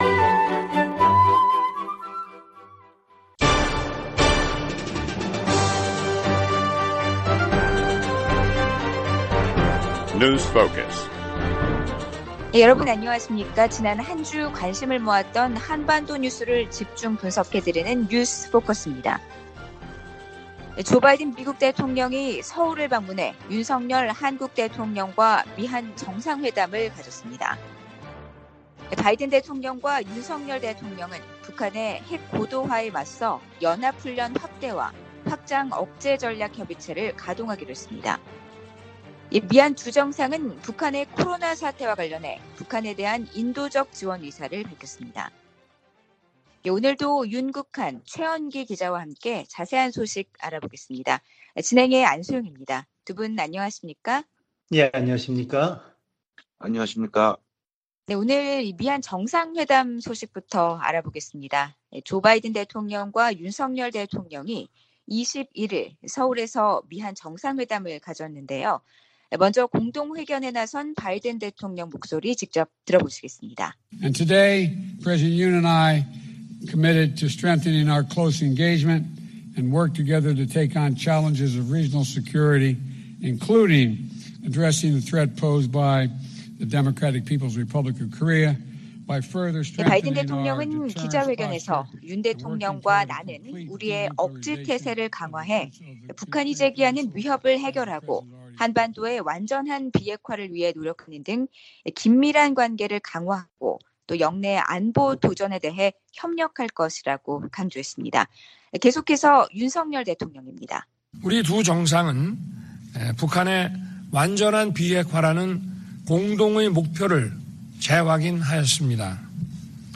VOA 한국어 방송의 월요일 오전 프로그램 2부입니다.